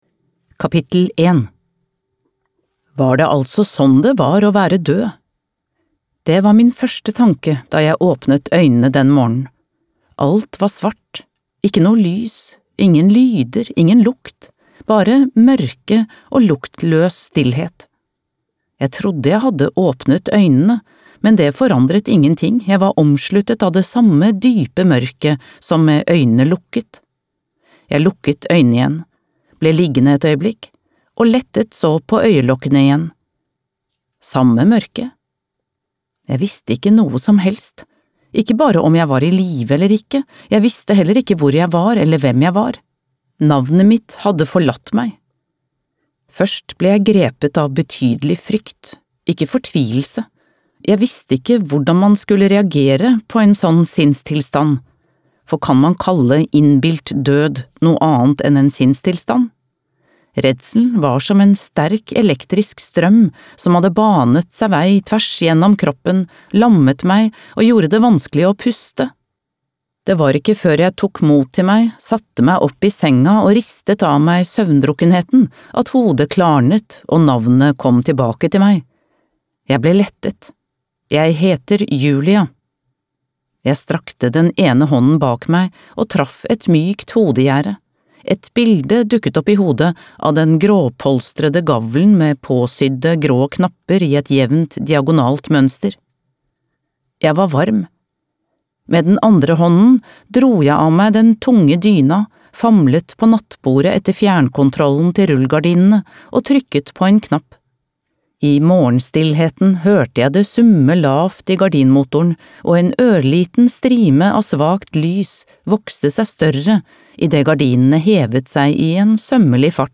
Ett sant ord (lydbok) av Snæbjörn Arngrímsson